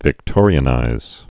(vĭk-tôrē-ə-nīz)